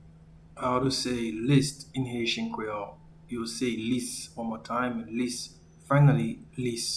Pronunciation:
List-in-Haitian-Creole-Lis.mp3